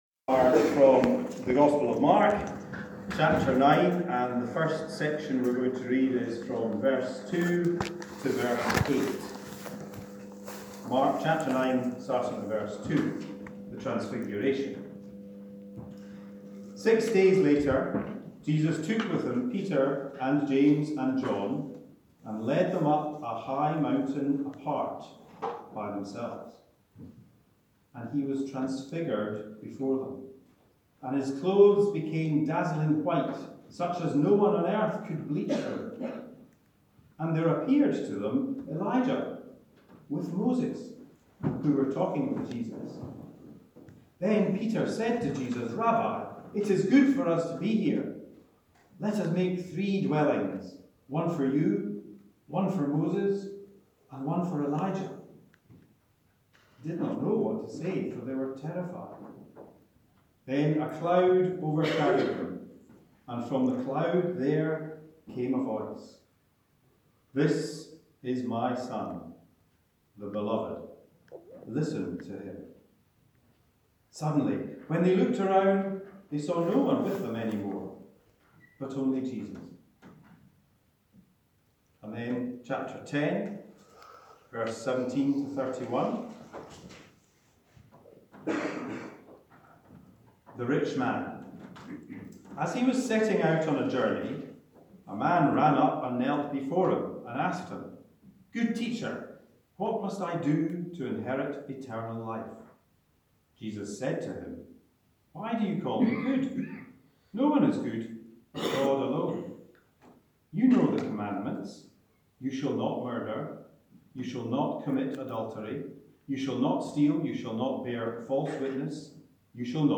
Journeying to Jerusalem- Sermon 7th April 2019 – NEWHAVEN CHURCH
sermon-7th-april-2019.mp3